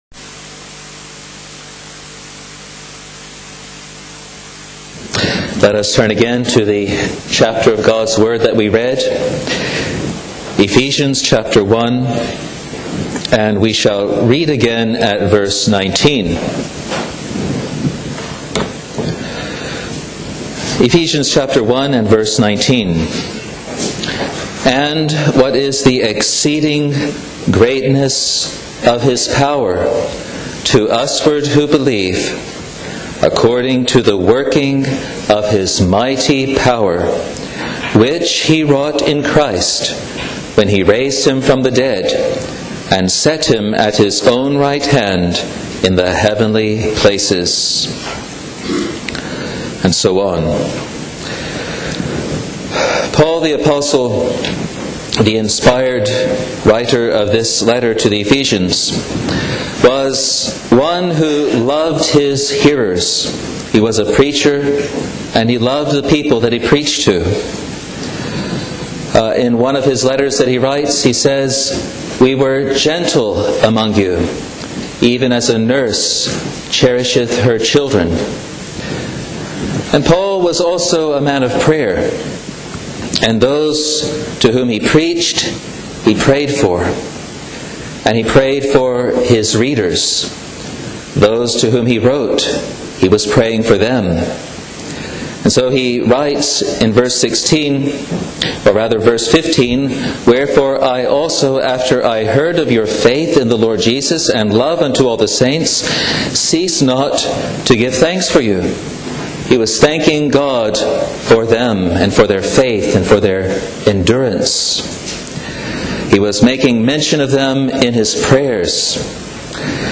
Resurrection Power in Conversion Sabbath AM | Free Presbyterian Church of Scotland in New Zealand